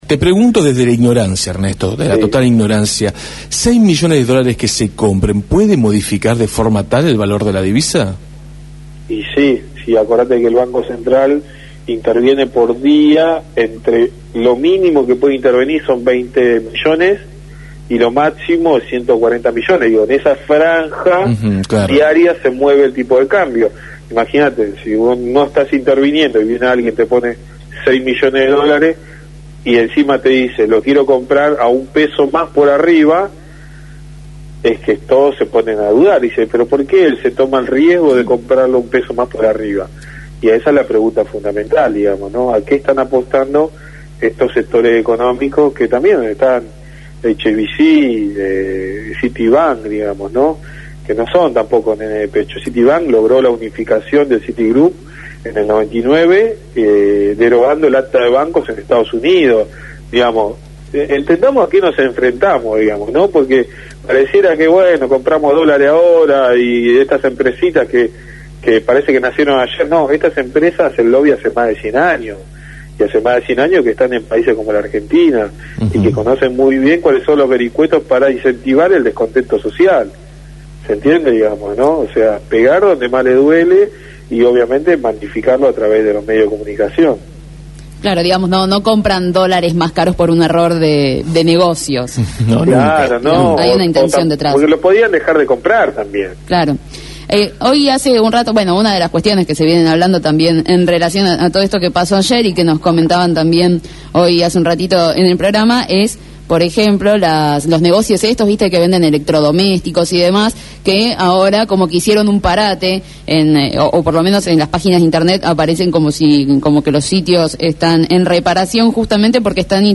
fue entrevistado en Desde el Barrio sobre el panorama económico y explicó que «esta medida pone sobre la mesa quién es el que administra el tipo de cambio en Argentina».